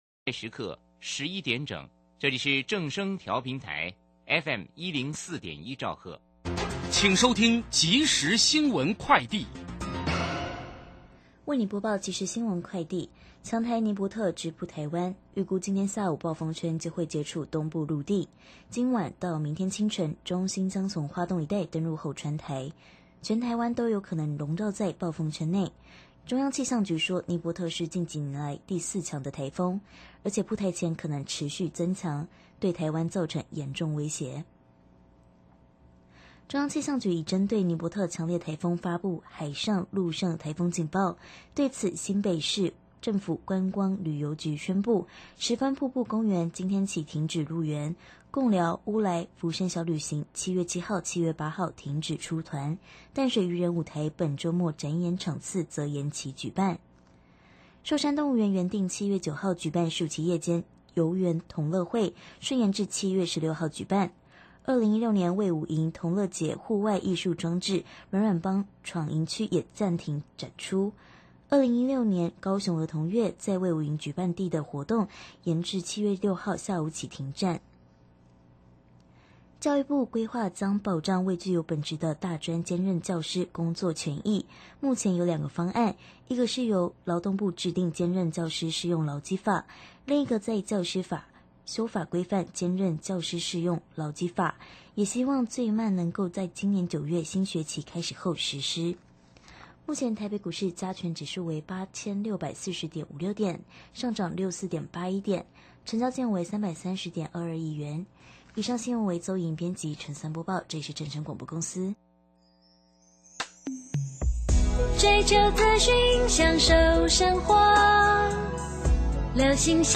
受訪者： 台北地檢蔡碧玉檢察長 節目內容： 酒駕案氾濫，北檢/北間開辦戒癮治療 Q&A：哪些情況會被認為是虐待